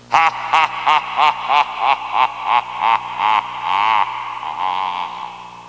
Cackling Intro Sound Effect
Download a high-quality cackling intro sound effect.
cackling-intro-1.mp3